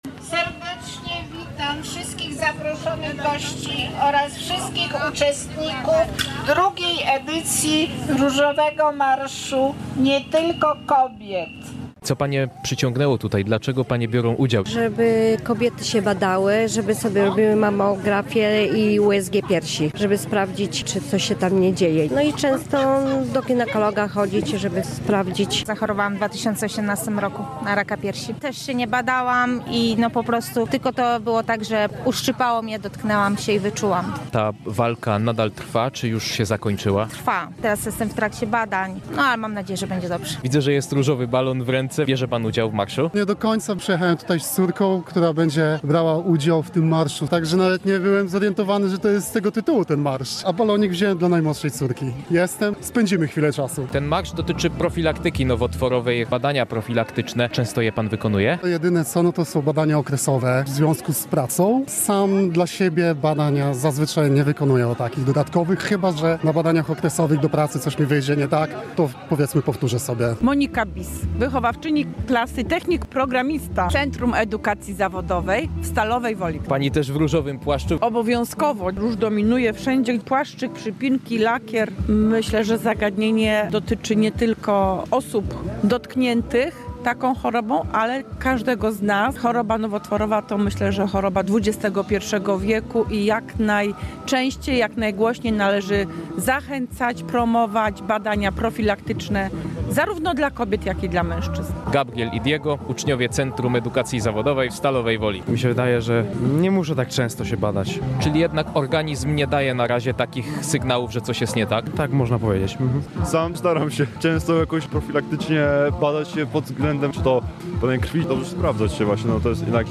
Marszowi towarzyszyła orkiestra dęta.